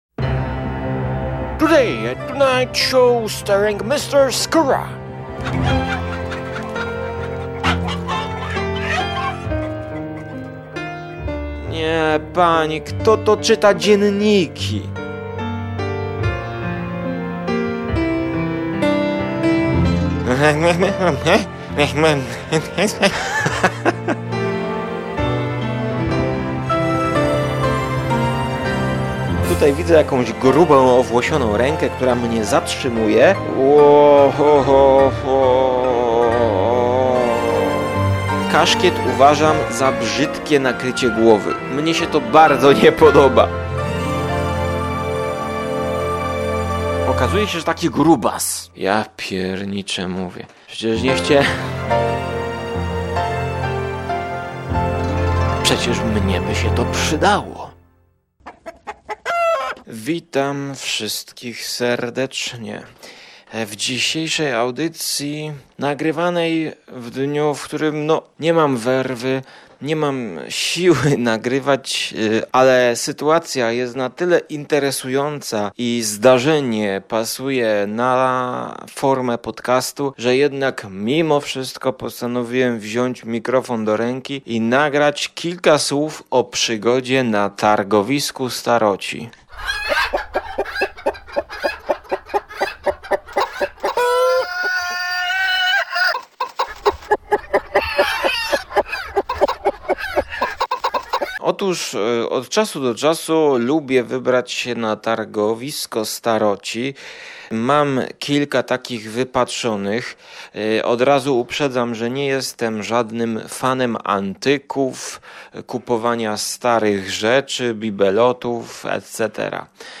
PRZYGODA na targowisku antyków! Opowieść w stylu starych pryków, którzy nudzą nad ogniskiem, przegryzając fasolę z puszki ;)
Audycja o kulturze: filmie, muzyce, o książkach i wszystkim co związane ze słowem "kultura".